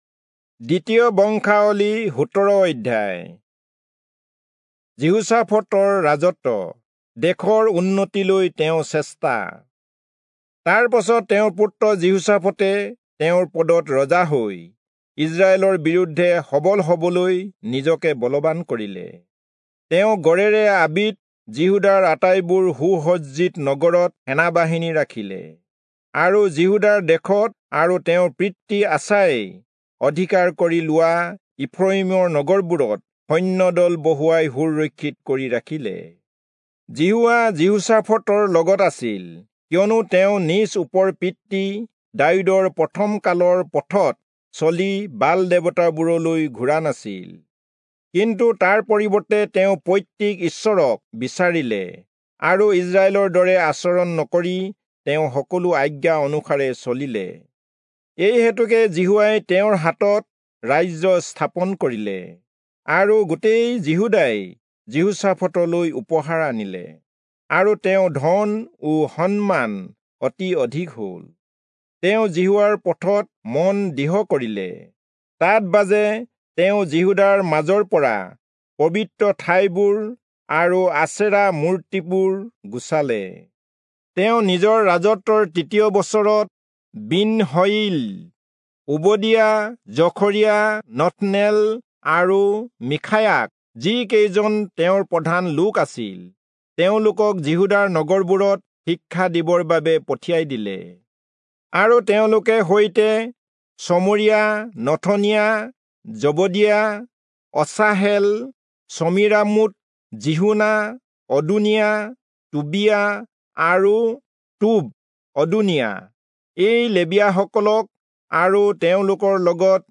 Assamese Audio Bible - 2-Chronicles 21 in Guv bible version